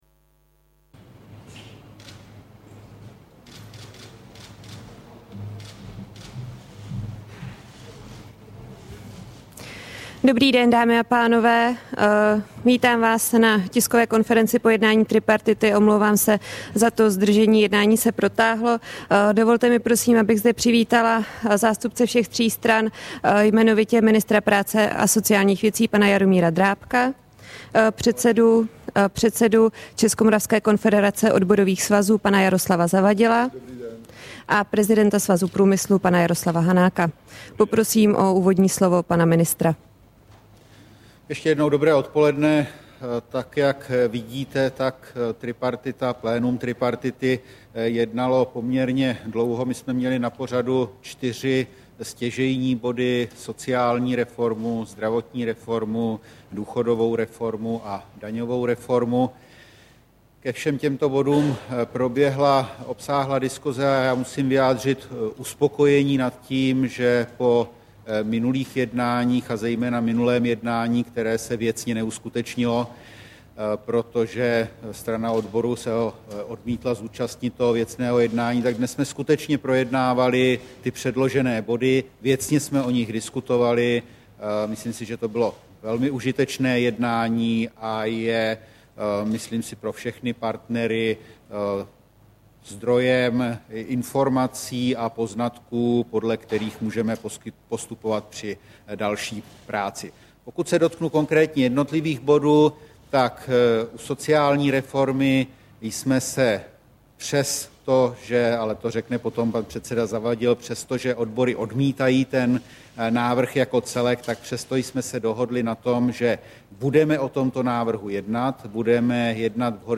Tisková konference po jednání tripartity, 20. května 2011